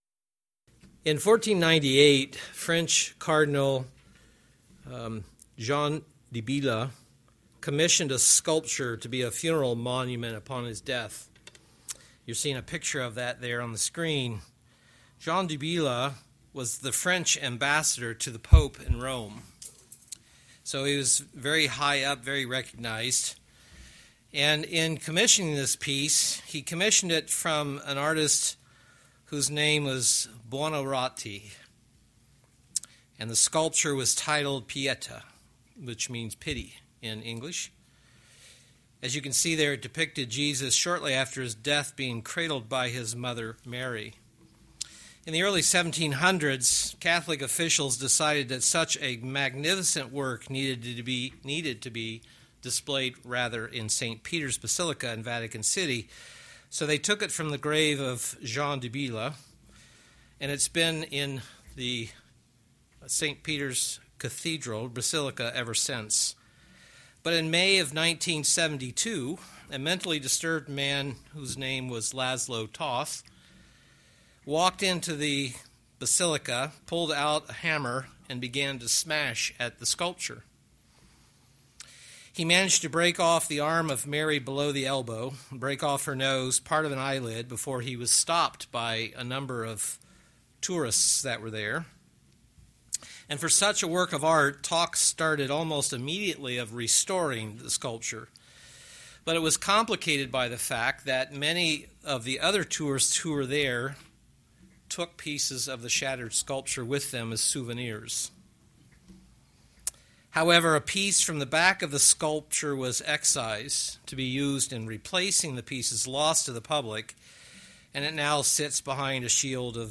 In this sermon we are going to consider this matter of being broken before God, and what it means to be made whole again.
Given in Milwaukee, WI